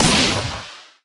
princess_shoot_01.ogg